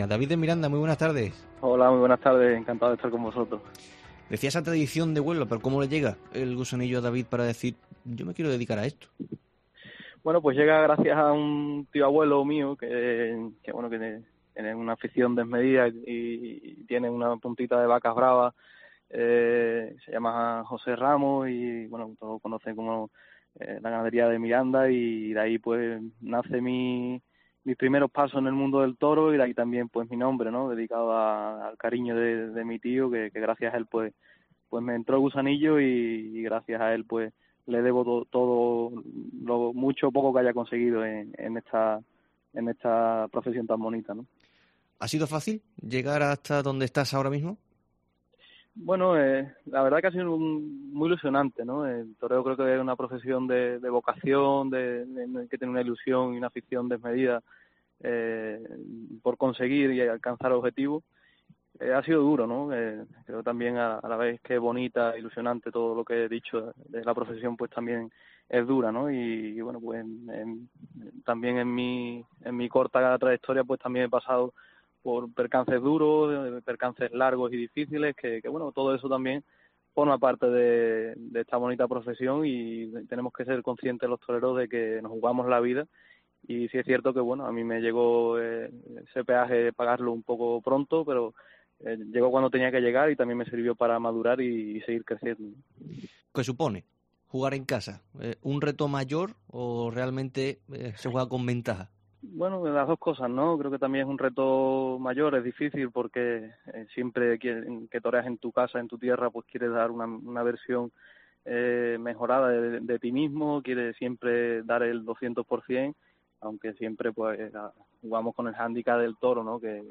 El diestro onubense relató en COPE Andalucía cómo está viviendo estos tiempos de pandemia y habló de la situación actual de la Fiesta.
El torero onubense fue otro de los protagonistas de La Tarde de COPE Andalucía esta semana.